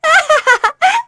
Sonia-vox-Happy1.wav